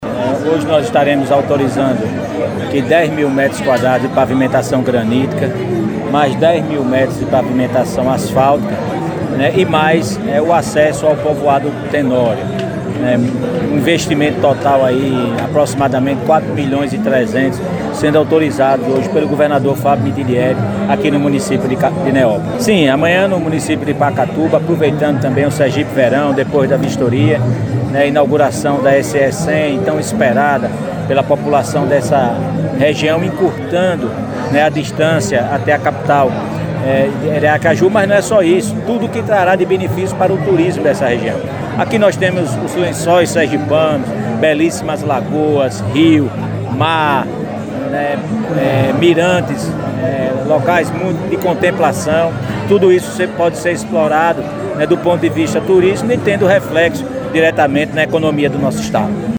FÁBIO MITIDIERI, GOVERNADOR DE SERGIPE
JORGE ARAÚJO FILHO, SECRETÁRIO DE ESTADO CHEFE DA CASA CIVIL
LUIZ ROBERTO, SECRETÁRIO DE ESTADO DO DESENVOLVIMENTO URBANO E INFRAESTRUTURA
CLAÚDIO MITIDIERI, SECRETÁRIO DE ESTADO DA SAÚDE
ALLYSSON DE AMINTAS, PREFEITO DE NEÓPOLIS